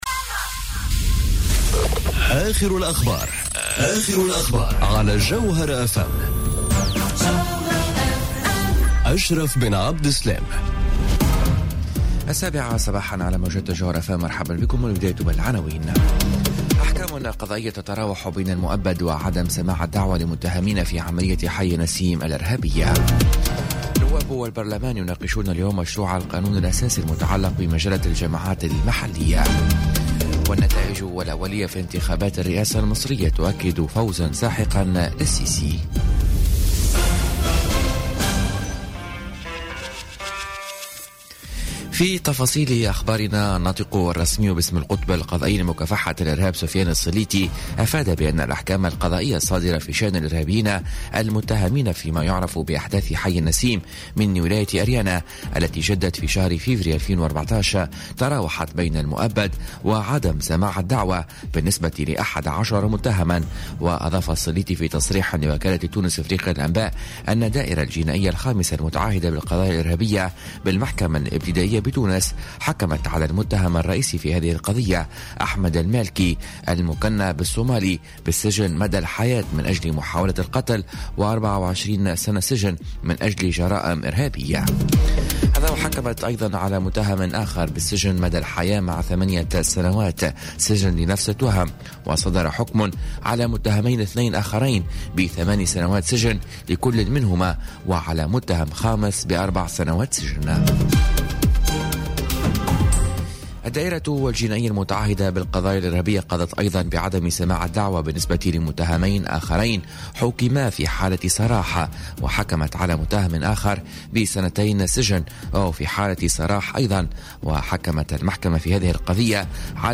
نشرة أخبار السابعة صباحا ليوم الخميس 29 مارس 2018